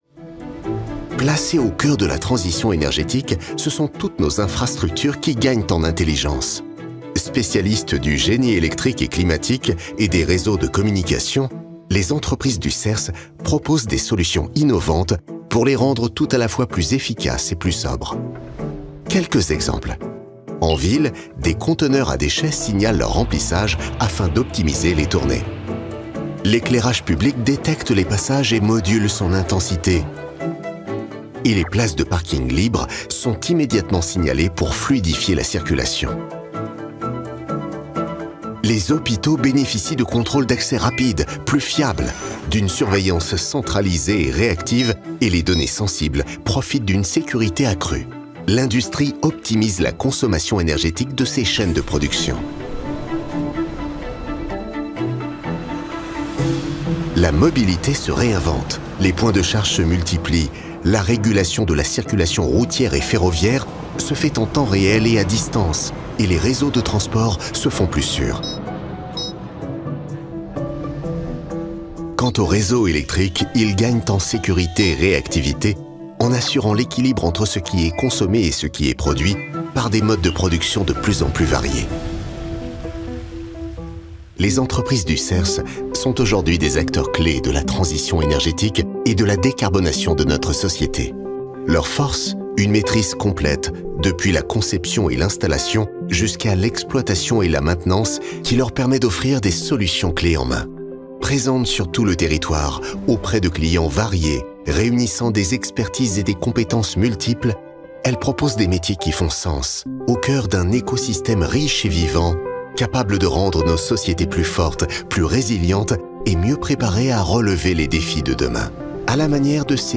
Film corporate "Serce" avec voix off homme positive et précise
Naturel et positif.
Comédien voix off pour les films institutionnels du SERCE.